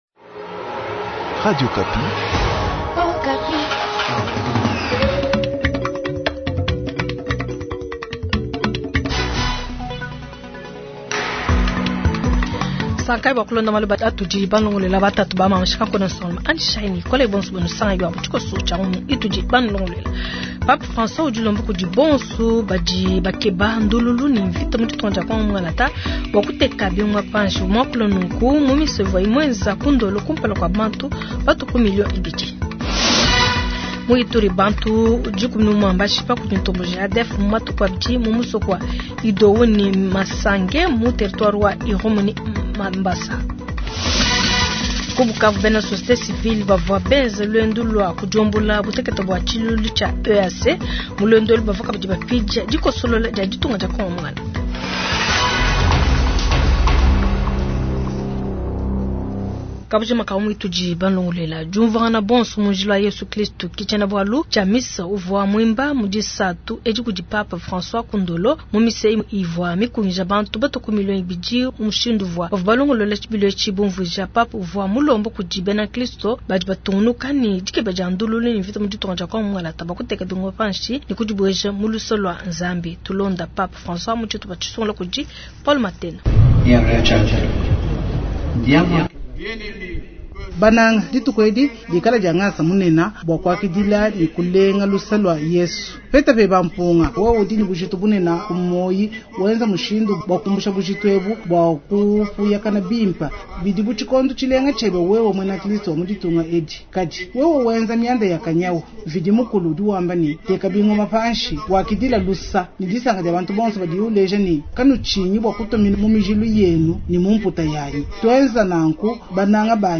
Journal soir
Mbandaka : Vox-pop : Attentes de la population de Mbandaka a la visite de Pape Francois